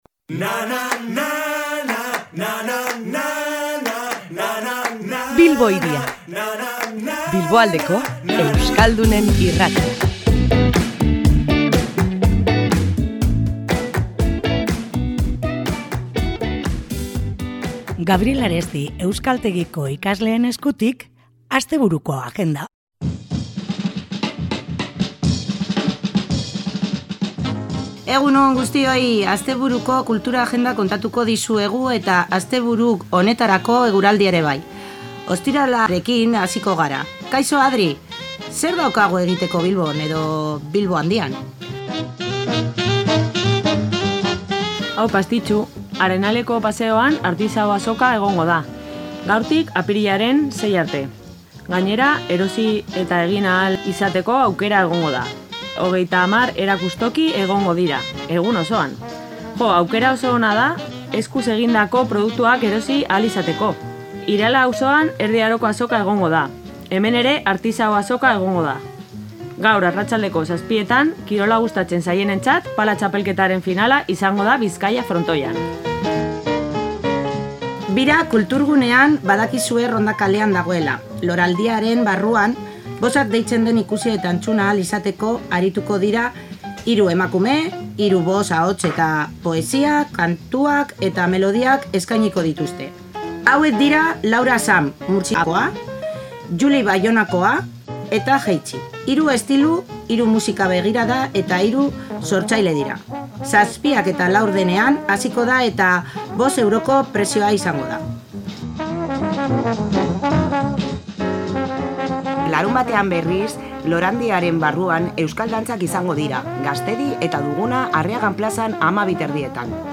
Asteburua ate joka dugu, eta ohikoa denez, agendari begirada bat botatzeko unea iritsi da. Gaur, Gabriel Aresti euskaltegiko ikasleak izan ditugu Bilbo Hiria irratian, eta haiek ekarritako proposamenekin astebururako plan erakargarriak ekarri dizkigute.